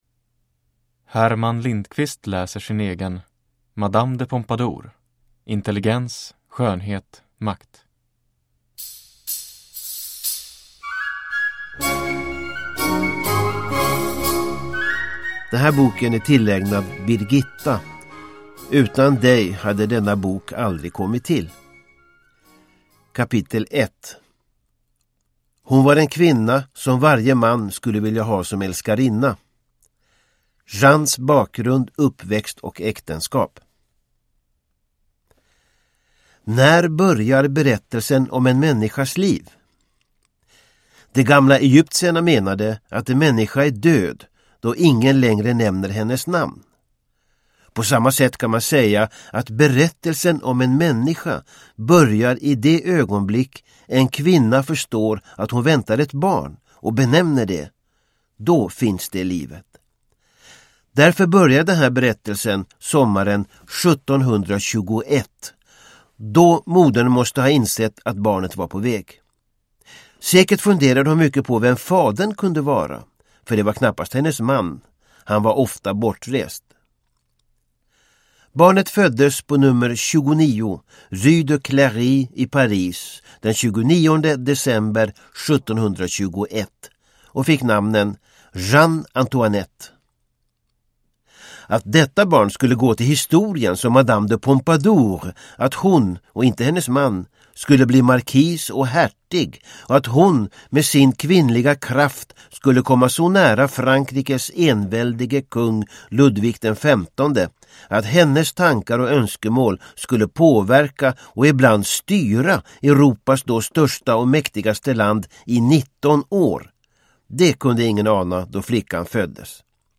Nedladdningsbar Ljudbok
Herman Lindqvist